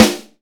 Tuned snare samples Free sound effects and audio clips
• Smooth Steel Snare Drum Sound G# Key 410.wav
Royality free snare sample tuned to the G# note. Loudest frequency: 2161Hz
smooth-steel-snare-drum-sound-g-sharp-key-410-sLm.wav